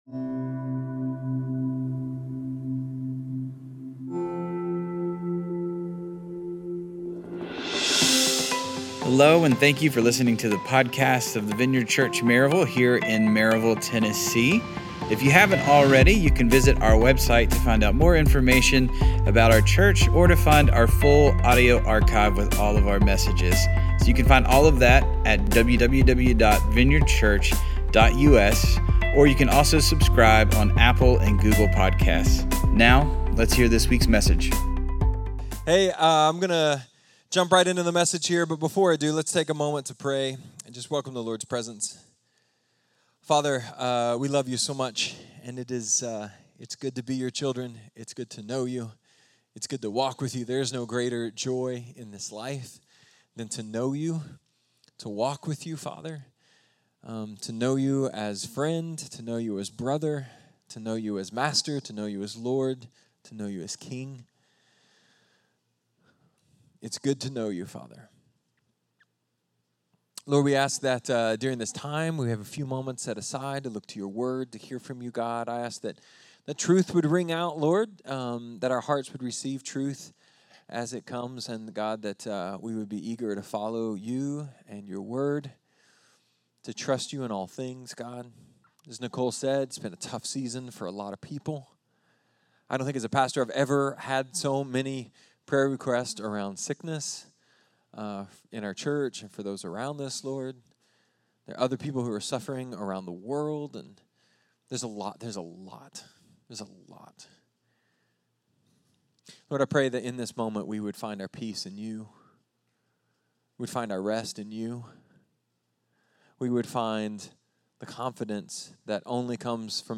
A sermon about how the church must address her hypocrisy, oppose unloving characterizations, and demonstrate love and welcome to all people.